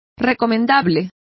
Complete with pronunciation of the translation of commendable.